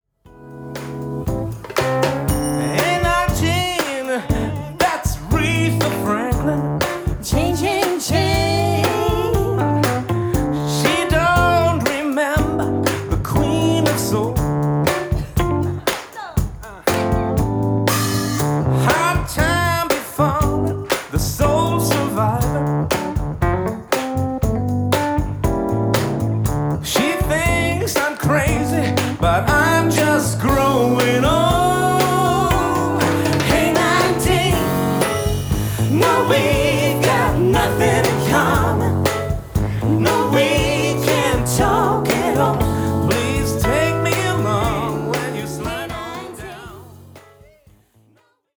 Die Beispiele sind sorgfältig auf gleiche Lautheit eingestellt, damit Sie auch wirklich die Klangqualitäten und nicht die Lautheit beurteilen.